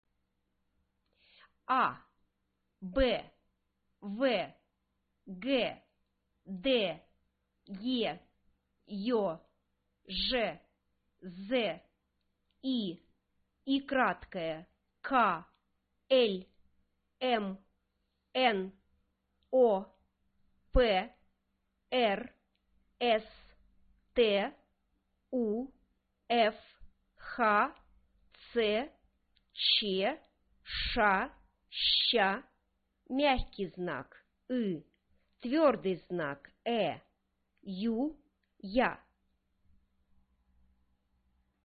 Cyrillic Character and pronunciation. (キリル文字と発音)